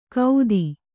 ♪ kaudi